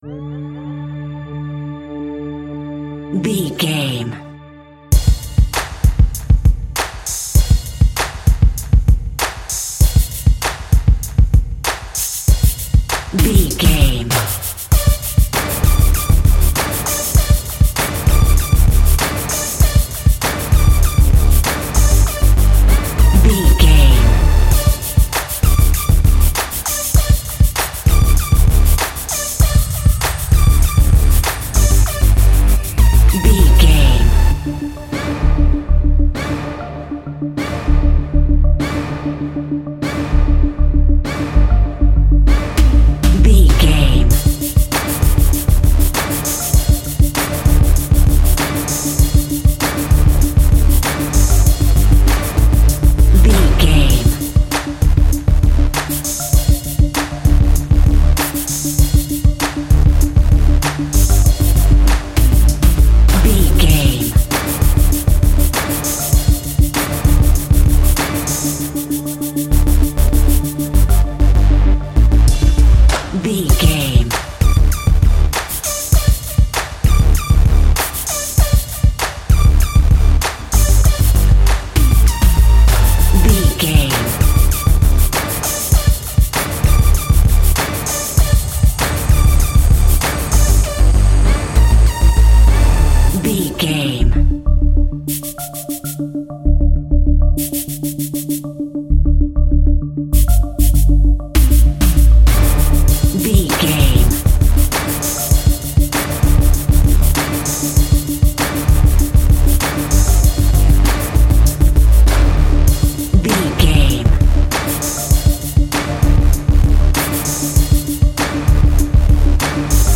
Aeolian/Minor
drum machine
synthesiser
hip hop
Funk
neo soul
acid jazz
confident
energetic
bouncy
funky